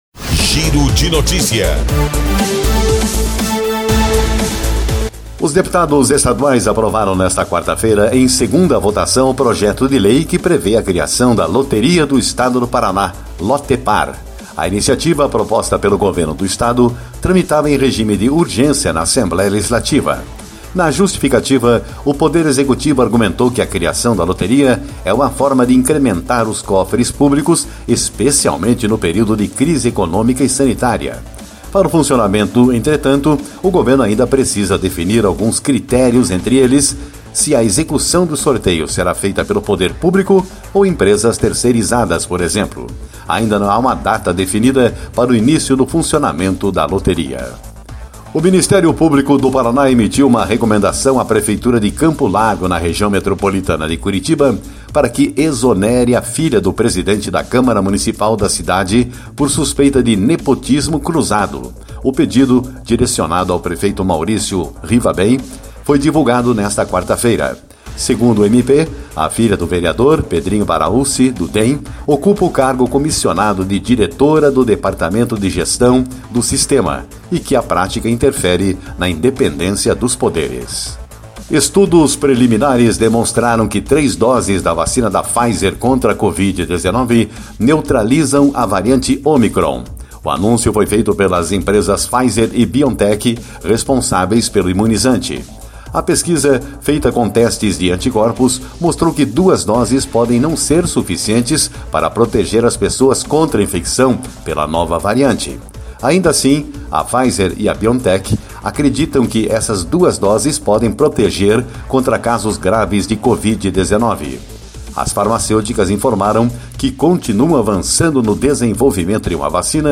Giro de Notícias Tarde